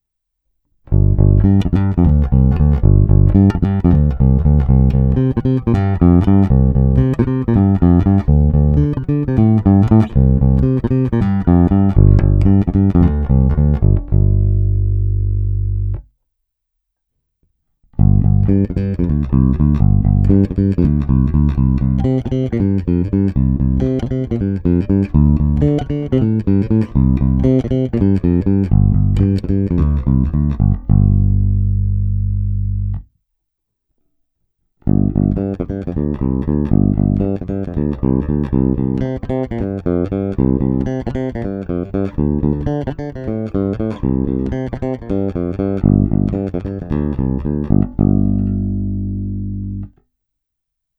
Není-li uvedeno jinak, jsou ukázky nahrány rovnou do zvukové karty a jen normalizovány.